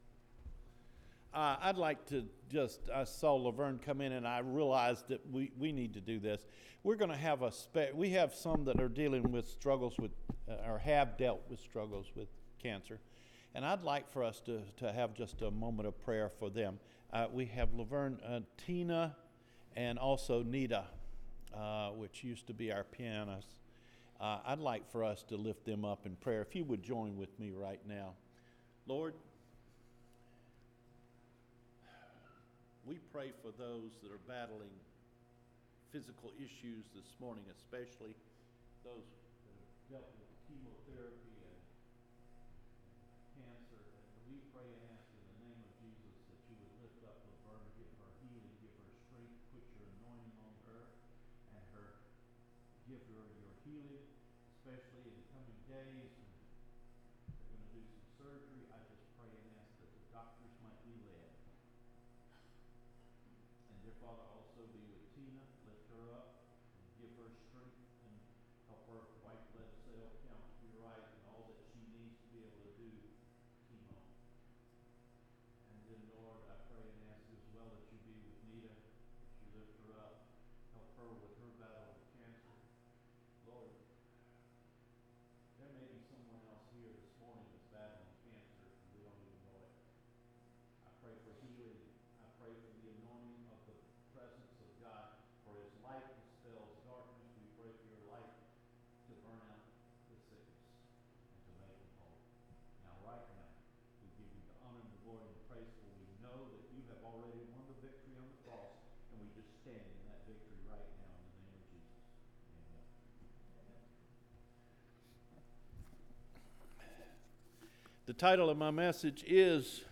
DELIVERANCE FROM AN UNGRATEFUL HEART – NOVEMBER 24 SERMON